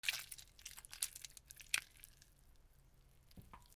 みかんを潰す
/ H｜バトル・武器・破壊 / H-94 ｜グロテスク、汁物 / 2_ミカン(みかん)